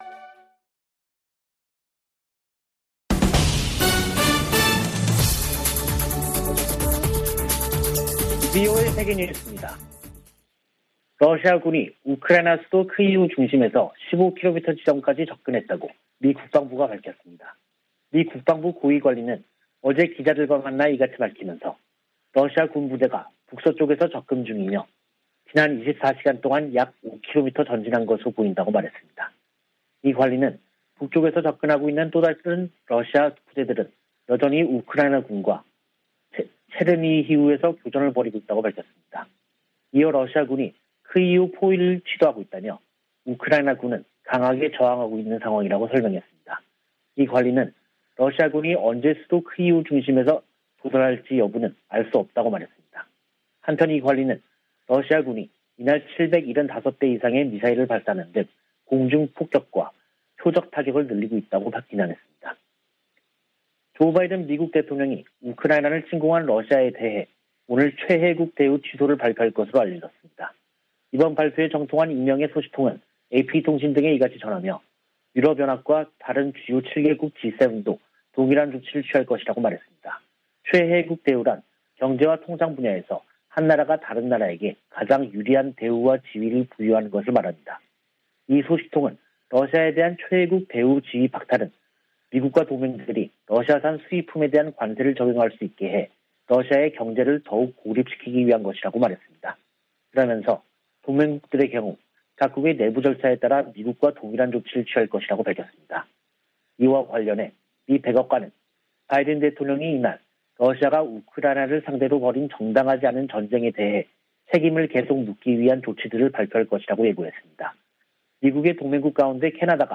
VOA 한국어 간판 뉴스 프로그램 '뉴스 투데이', 2022년 3월 11일 3부 방송입니다. 미 국무부는 북한 핵·미사일 위협을 한국의 윤석열 차기 정부와 핵심 협력 사안으로 꼽았습니다. 미국 주요 언론은 한국에 보수 정부가 들어서면 대북정책, 미한동맹, 대중국 정책 등에서 큰 변화가 있을 것으로 전망했습니다. 북한이 폭파했던 풍계리 핵실험장을 복구하고 금강산에 있는 한국 측 시설을 철거하는 움직임이 포착된 것으로 알려졌습니다.